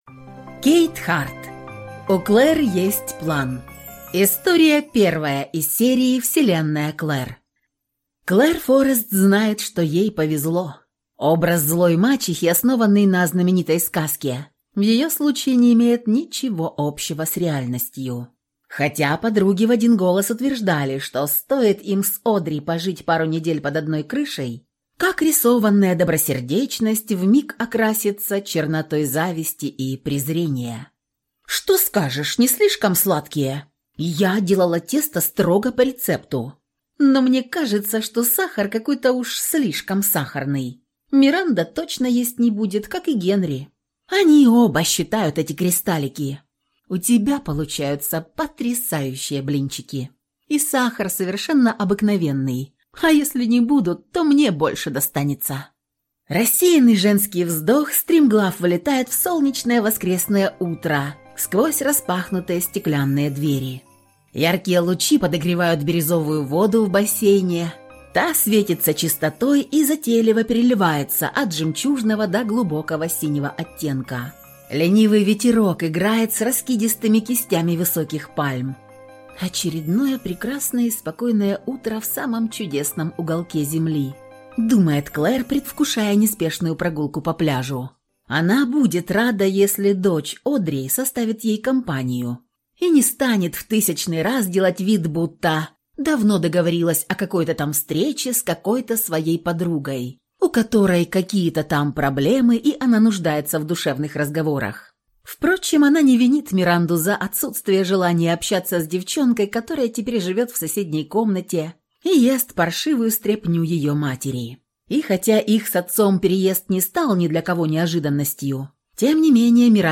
Аудиокнига У Клер есть план | Библиотека аудиокниг